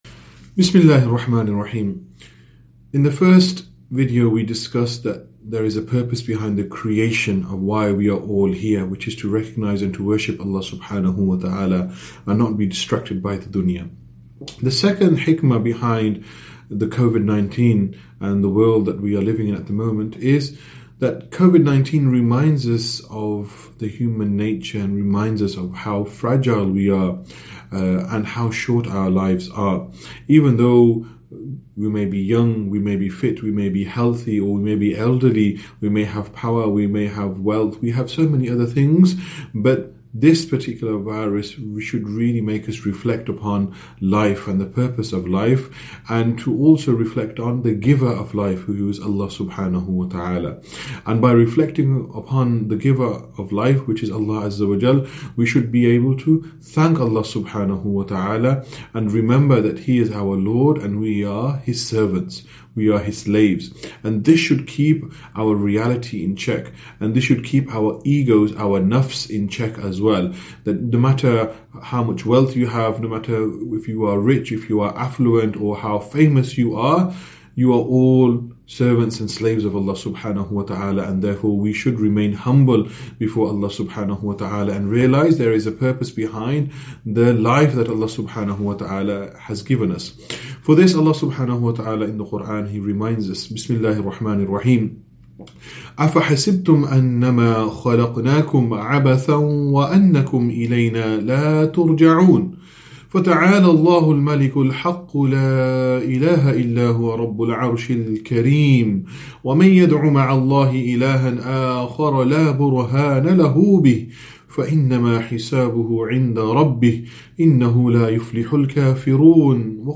Sermons 2020 – Ashton Central Mosque